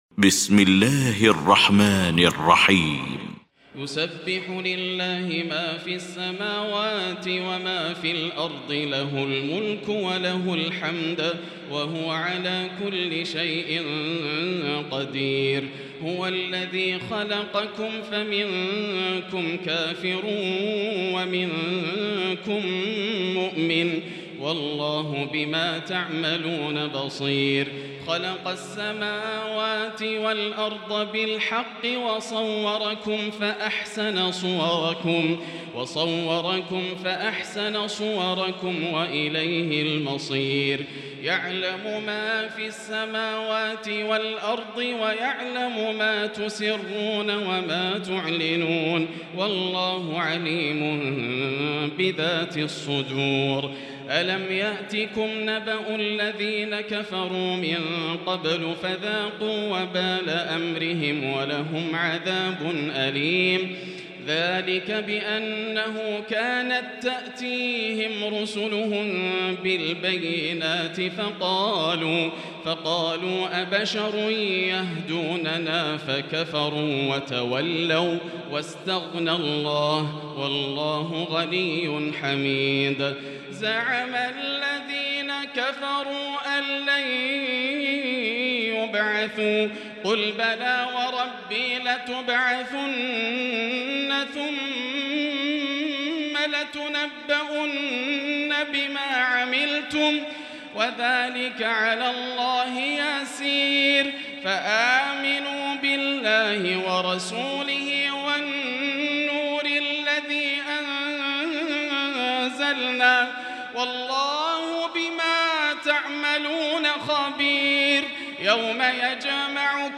المكان: المسجد الحرام الشيخ: فضيلة الشيخ ياسر الدوسري فضيلة الشيخ ياسر الدوسري التغابن